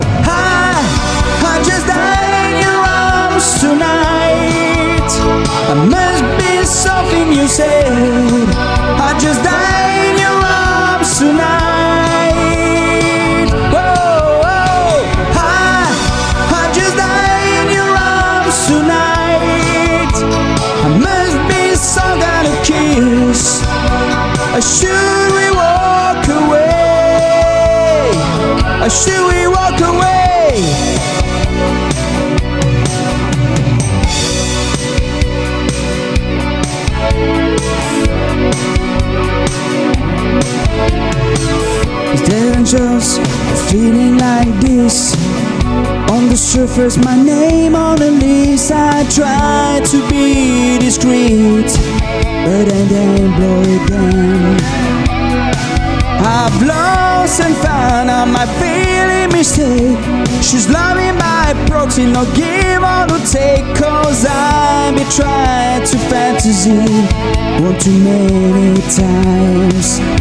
Studio Côtier, Frontignan, France.
Guitare
Chant, Choeurs
Basse